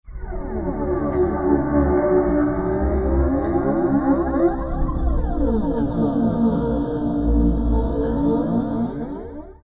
描述：用Magix音乐制作器制作的
标签： 100 bpm Ambient Loops Fx Loops 1.62 MB wav Key : Unknown
声道立体声